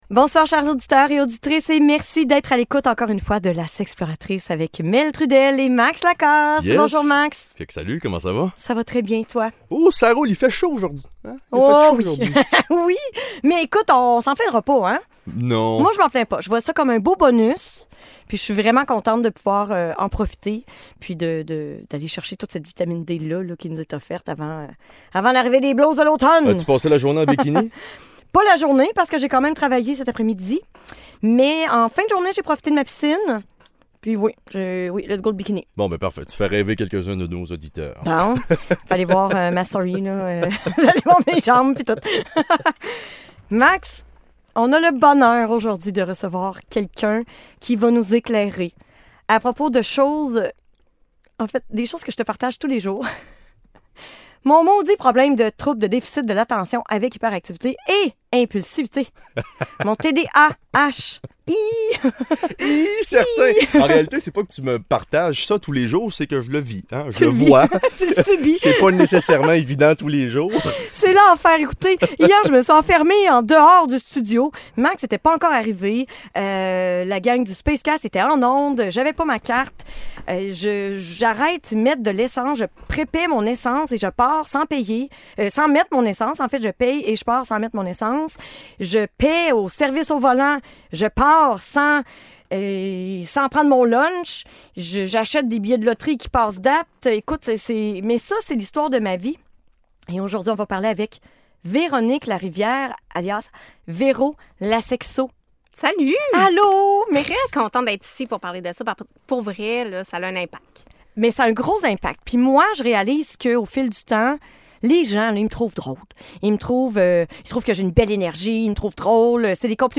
En entrevue ce soir